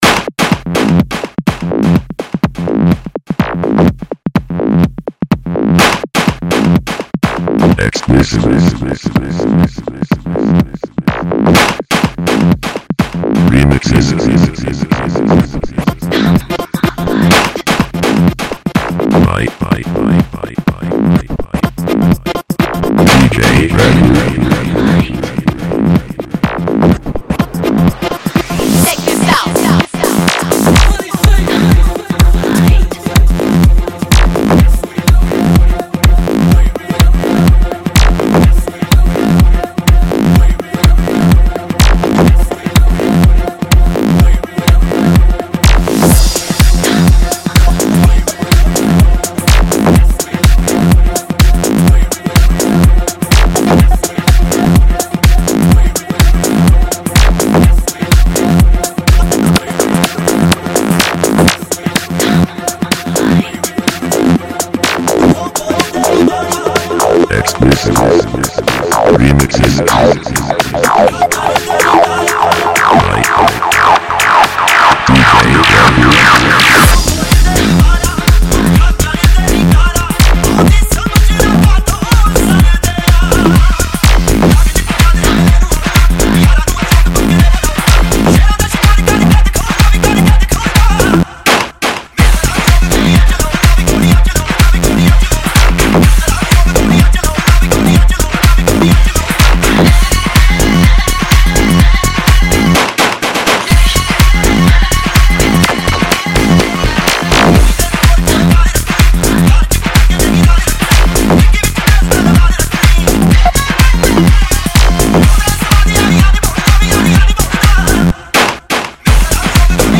Punjabi Bhangra MP3 Songs
DJ MIX